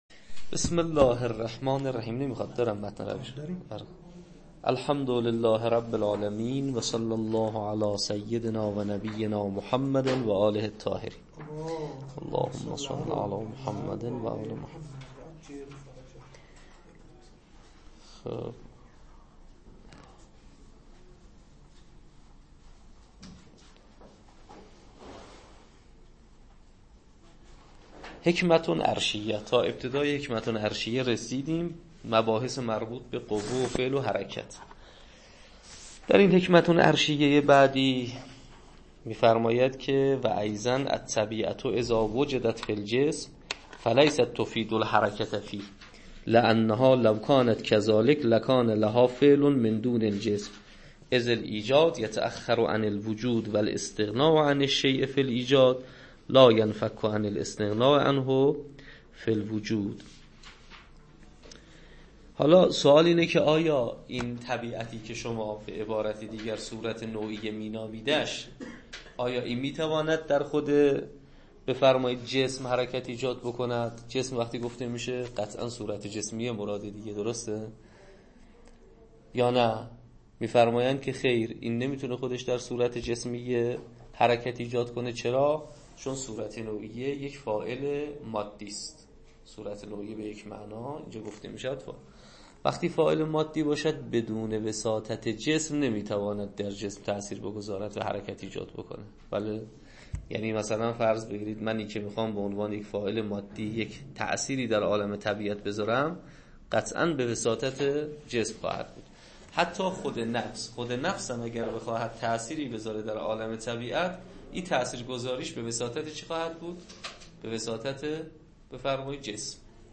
شواهد الربوبیه تدریس